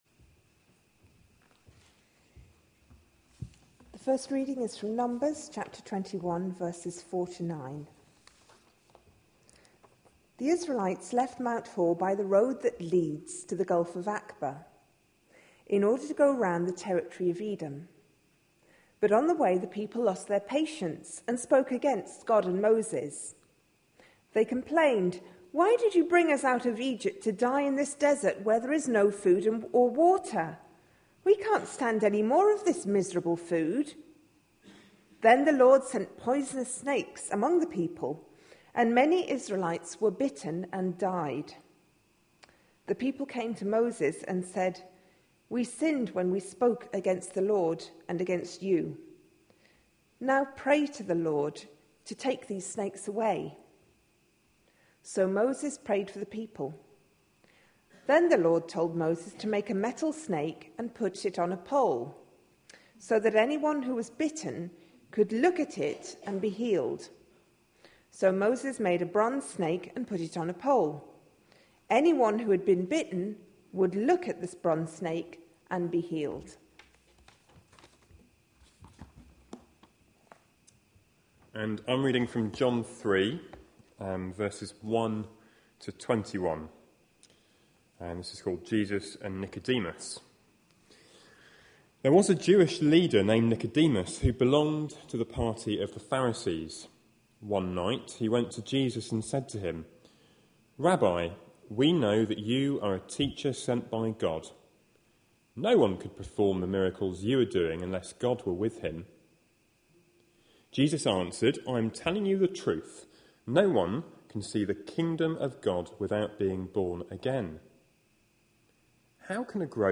A sermon preached on 12th February, 2012, as part of our Looking For Love (6pm Series) series.